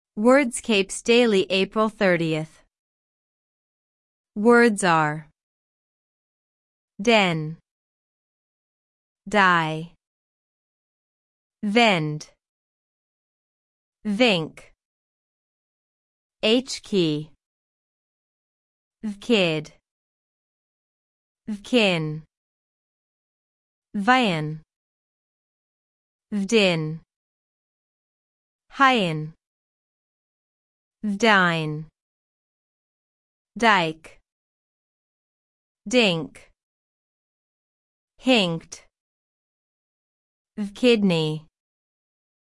On this page you’ll get the full Wordscapes Daily Puzzle for April 30 Answers placed in the crossword, all bonus words you can collect along the way, and an audio walkthrough that can read the answers to you at the speed you like while you’re still playing.